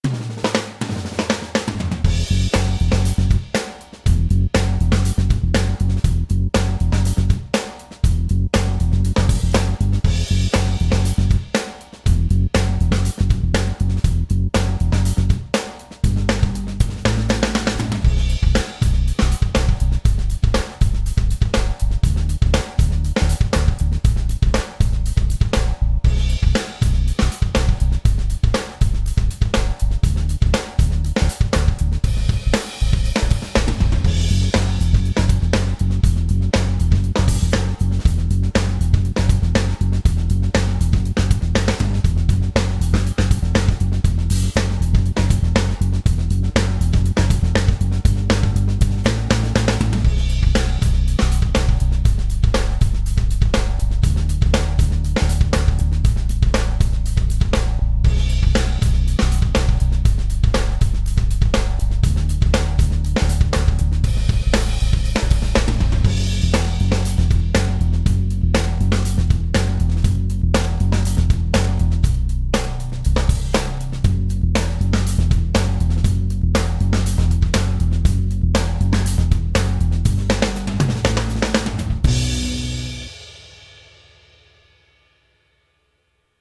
СТИЛЬ: АЛЬТЕРНАТИВА И ХЕВИ-МЕТАЛ
Чтобы сделать занятие более увлекательным, я записал так называемый "симулятор группы" — специальный трек, в котором только ударные и бас, чтобы вы могли под него тренироваться:
Здесь приведены дорожки аккомпанемента в трёх разных темпах: 100, 110 и 120 ударов в минуту:
120 BPM
metal-song-minus-one-track-120bpm.mp3